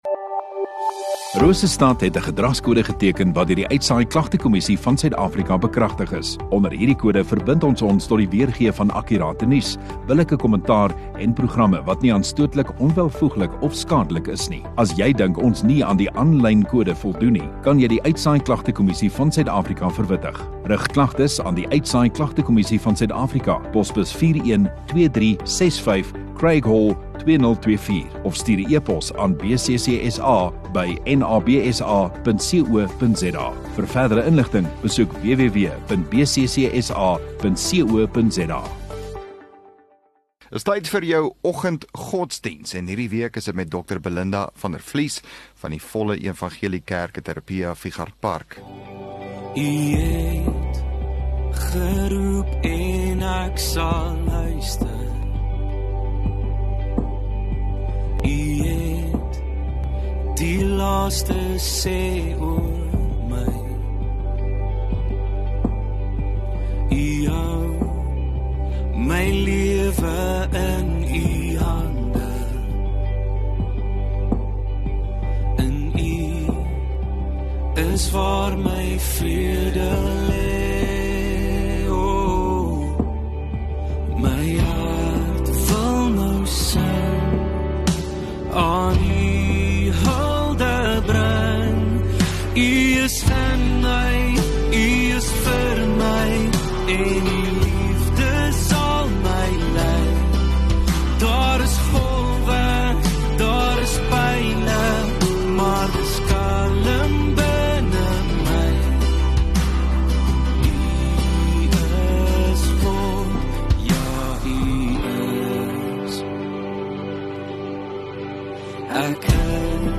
10 Mar Maandag Oggenddiens